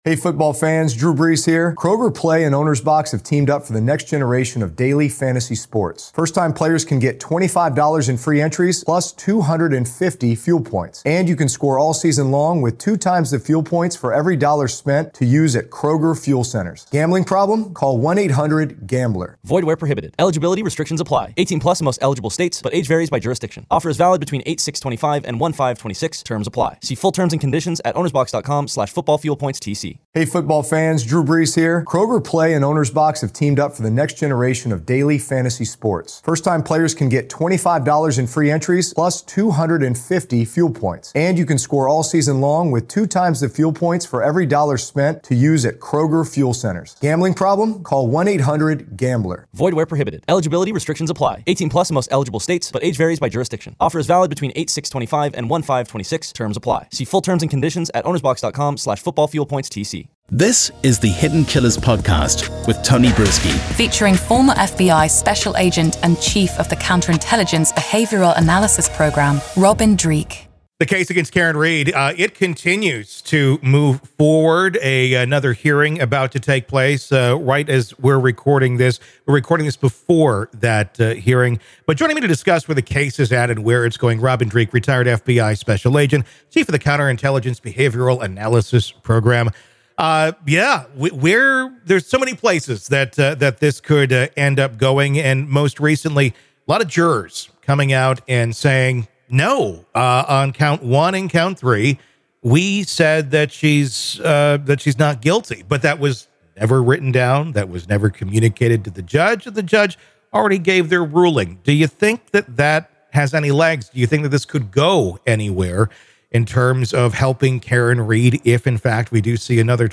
The conversation also delves into the internal investigations of officers involved in the case, highlighting how such probes can affect the credibility of evidence and testimonies.